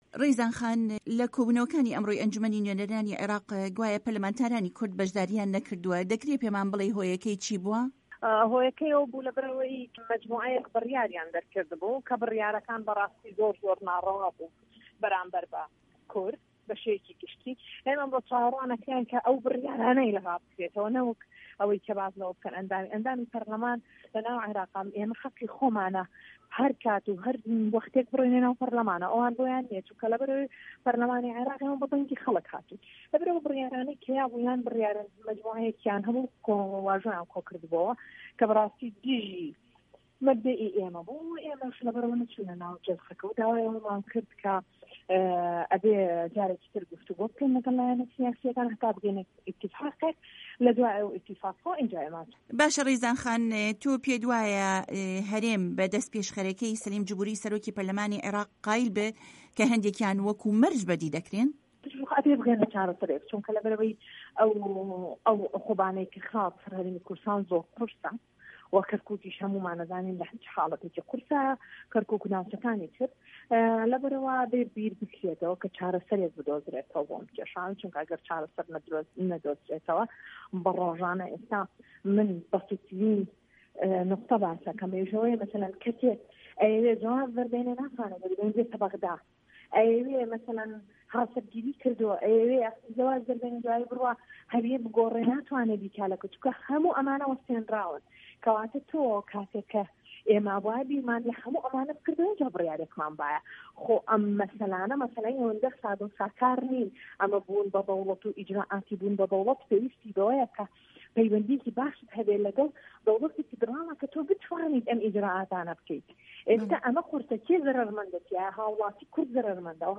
رێزان شێخ دلێر ئەندامی پەرلەمانی عیراق لە گفتوگۆیەکدا لەگەڵ بەشی کوردی دەنگی ئەمەریکا دەڵێت پەرلەمانتارانی کورد خۆیان نەچۆنەتە کۆبوونەوەکانی پەرلەمان وداوایانکردووە هەڵویستی دژەبەرایەتی پەرلەمانتارانی عەرەب ڕابگیرێت کە دوای گشتپرسی هەرێم گرتوویانەتە بەر.
گفتوگۆ لەگەڵ رێزان شێخ دلێر